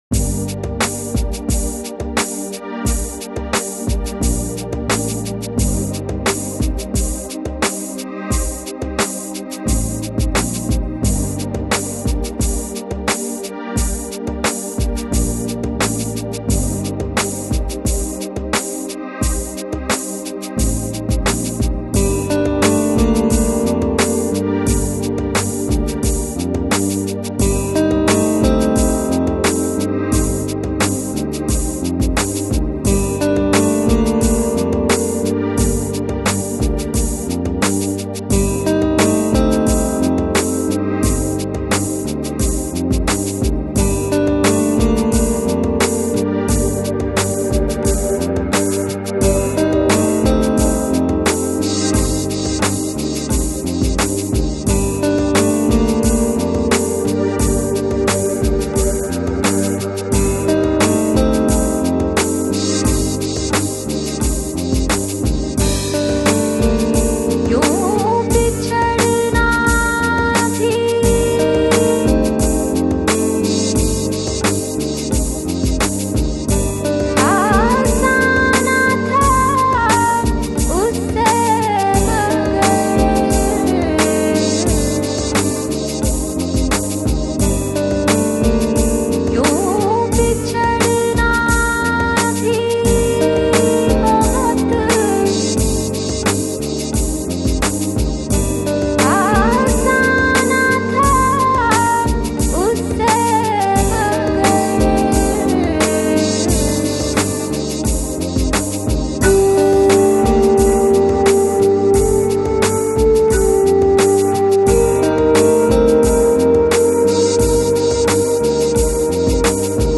Жанр: Balearic, Downtempo, Lounge